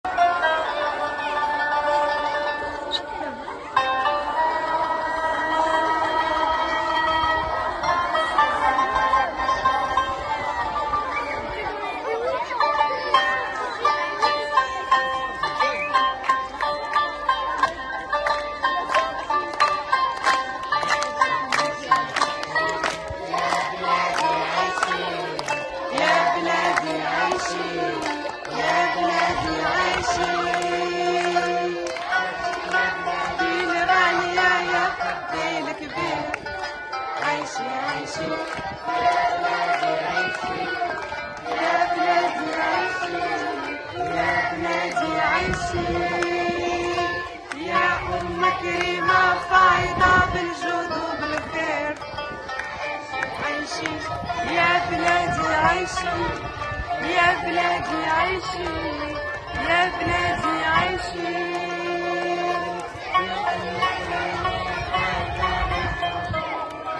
Les élèves participent à des chants, dont l’hymne national. Les CM2 ont découvert ce bel instrument qu’est le sitar et l’ont accompagné pour offrir une chorale aux autres élèves.